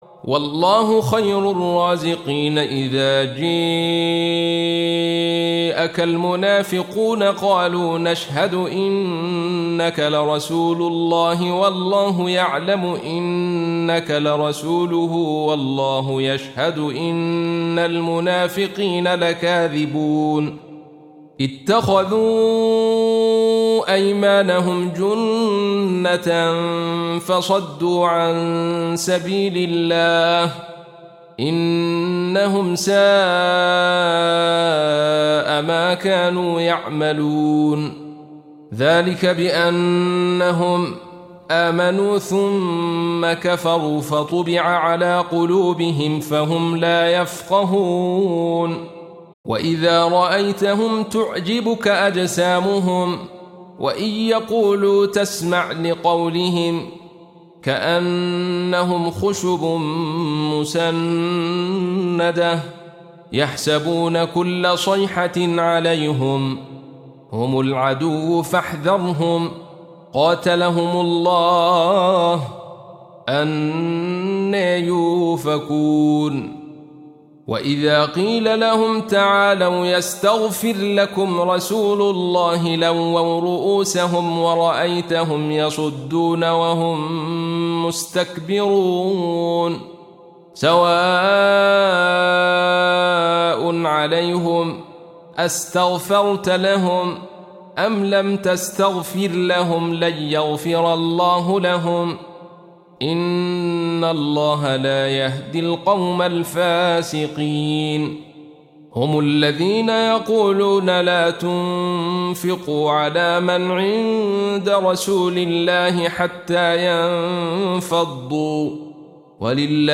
Surah Repeating تكرار السورة Download Surah حمّل السورة Reciting Murattalah Audio for 63. Surah Al-Munafiq�n سورة المنافقون N.B *Surah Includes Al-Basmalah Reciters Sequents تتابع التلاوات Reciters Repeats تكرار التلاوات